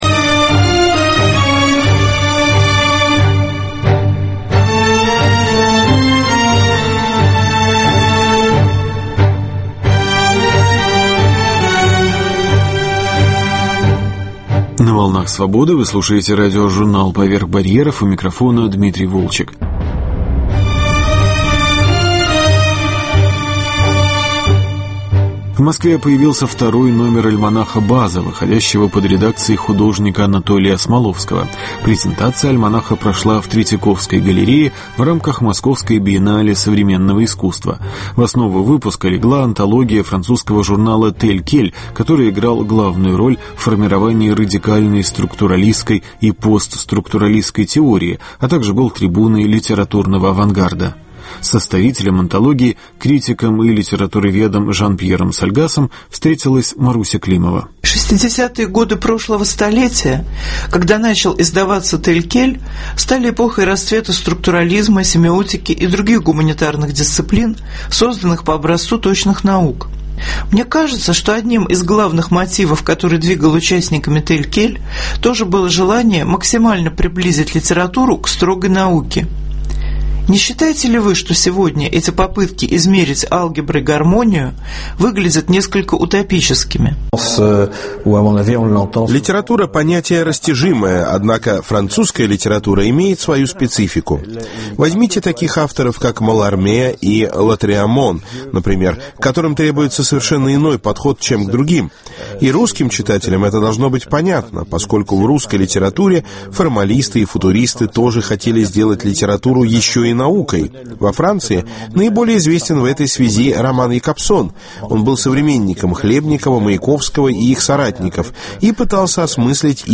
«Тель Кель» на русской почве. Беседа